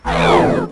head_drop.ogg